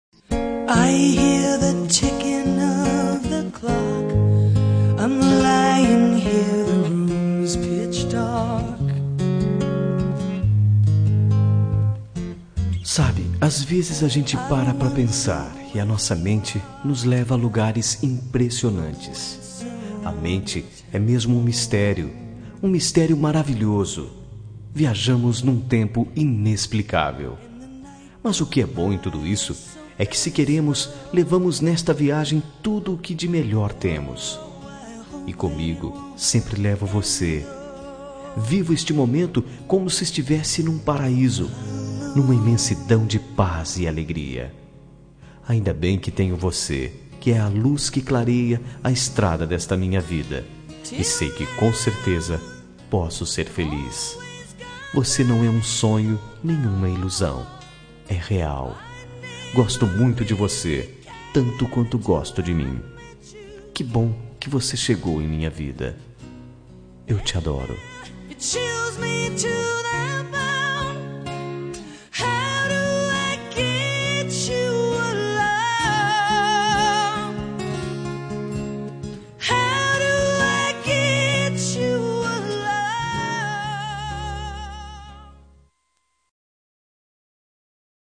Telemensagem Início de Namoro – Voz Masculina – Cód: 760